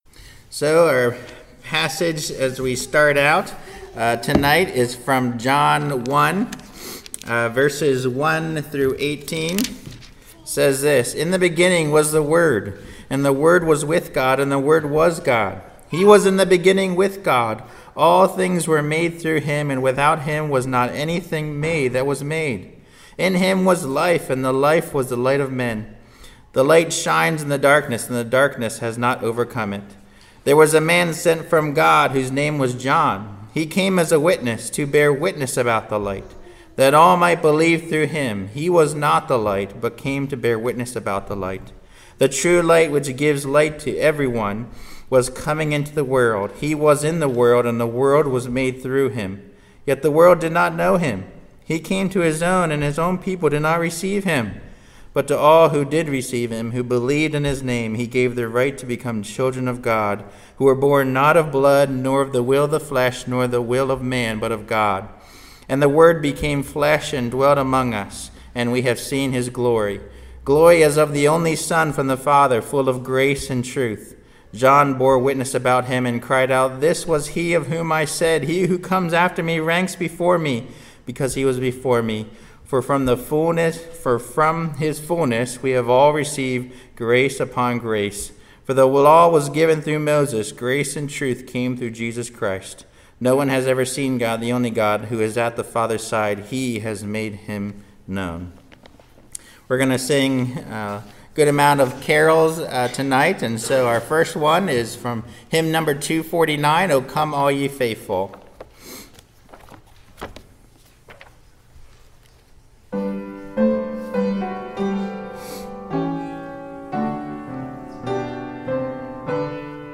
Christmas Eve Service 2020
Non-Series Sermon Passage: 1 Timothy 1:15 Topics